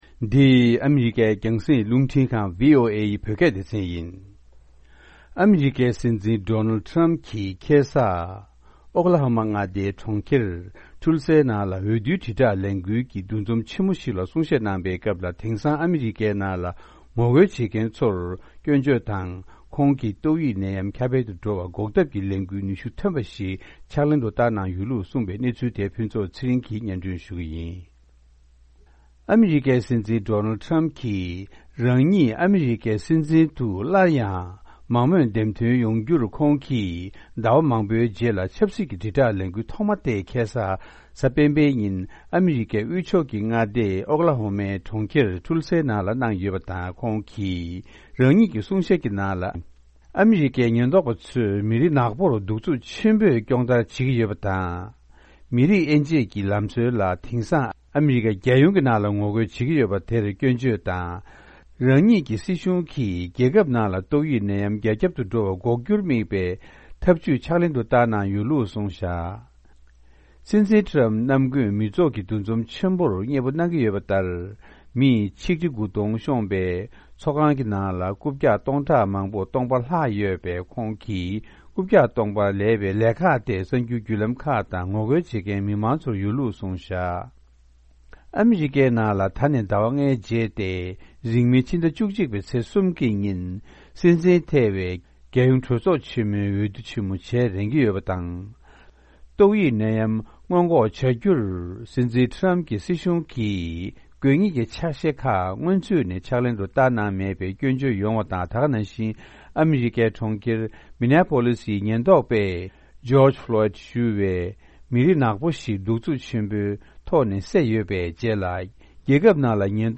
༄༅། །རེས་གཟའ་སྤེན་པའི་ཉིན་ཨ་རིའི་སྲིད་འཛིན་ཌོ་ནལ་ཊམ་གྱིས་ཨོཀ་ལ་ཧ་མ་མངའ་སྡེའི་གྲོང་ཁྱེར་ཏུལ་སའི་ནང་འོས་བསྡུའི་དྲིལ་བསྒྲགས་ལས་འགུལ་གྱི་འདུ་འཛོམས་ཆེན་མོ་ཞིག་ལ་གསུང་བཤད་གནང་སྐབས་དེང་སང་ཨ་རིའི་ནང་ངོ་རྒོལ་བྱེད་མཁན་ཚོར་སྐྱོན་བརྗོད་དང་ཏོག་དབྱིབས་ནད་ཡམས་ཁྱབ་འཕེལ་འགོག་ཐབས་ལ་ཁོང་གིས་གྲུབ་འབྲས་ཐོན་པའི་ཕྱག་ལས་གནང་ཡོད་ཅེས་གསུངས་འདུག